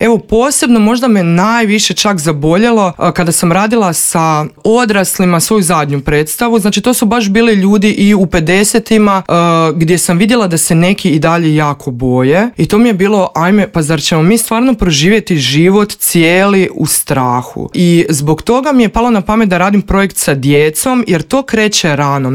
razgovarali smo u Intervjuu Media servisa.